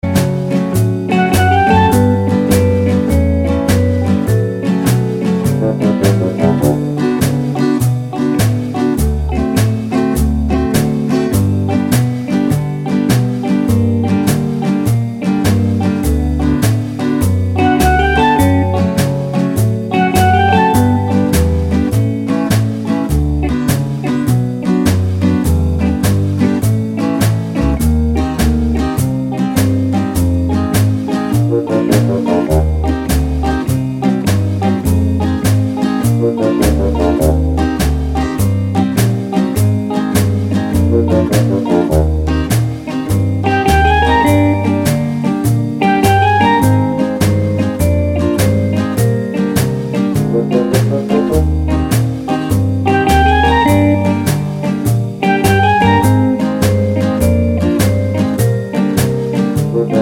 no Backing Vocals or whistle Crooners 2:43 Buy £1.50